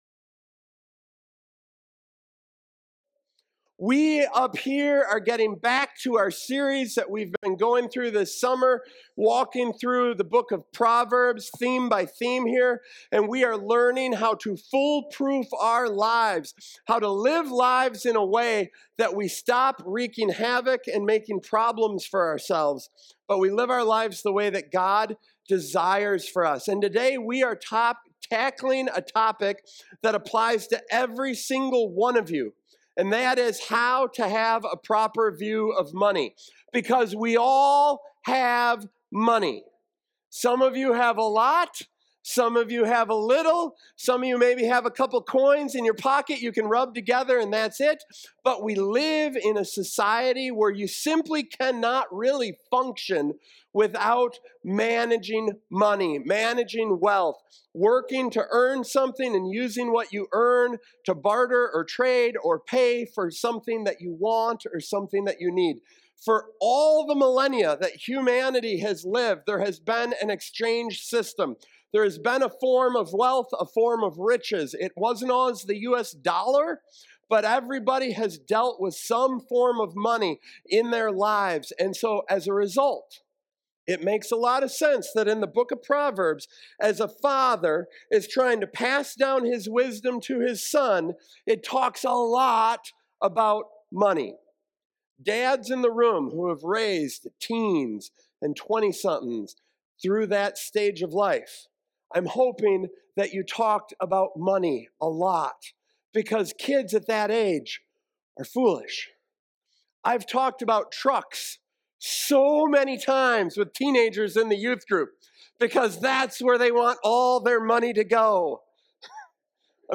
Proverbs Proverbs Watch Listen Save In this sermon, we explore the biblical perspective on money through the wisdom of Proverbs, discovering that God calls us to a balanced approach rather than the extremes of prosperity theology or poverty. We examine ten key principles including why both wealth and poverty bring unique temptations, how wisdom and diligence build wealth, the importance of generosity, and why money can’t provide true security.